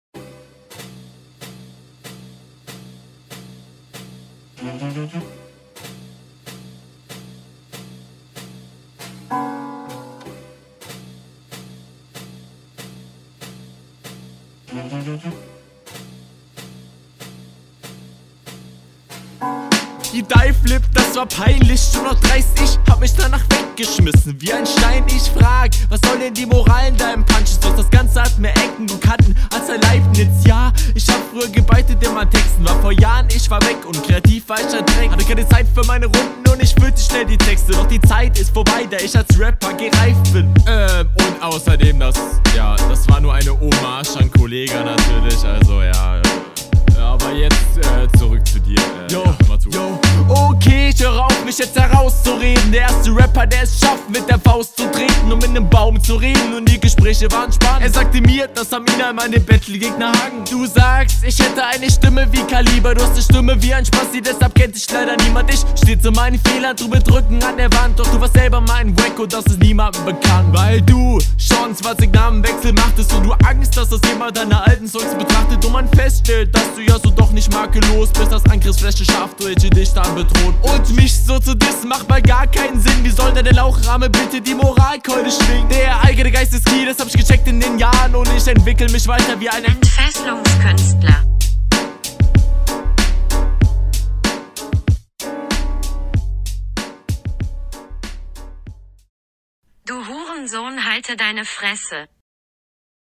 Du klingst auch routiniert.